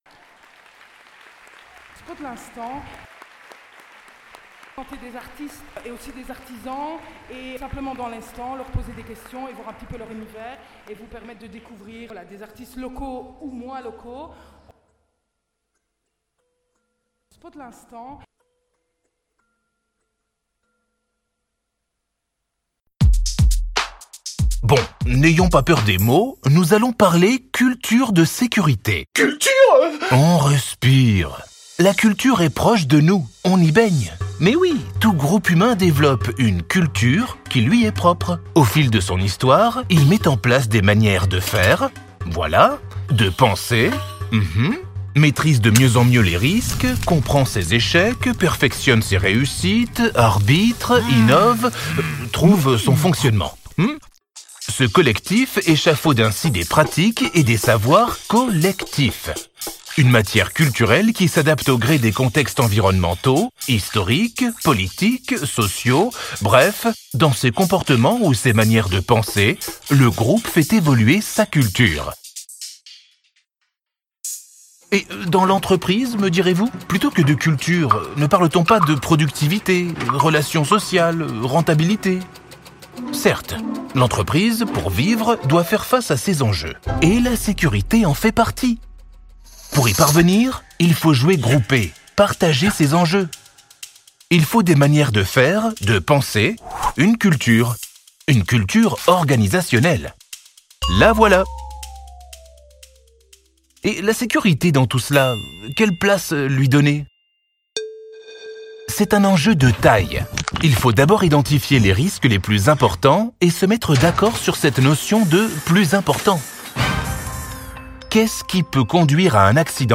Entre interviews et témoignages bienveillants, chacun (policier, ambulancier, pompier, Croix-Rouge, médecin urgentiste) partage avec nous son rôle, son fonctionnement et les particularités de son engagement, en direct pour RadioSud. Un ensemble de captations inédites.Un moment authentique à Chassepierre, capté au plus près, qui complète la série de podcasts Spot'L'instant : des rencontres où l'art se vit, se raconte et se transmet.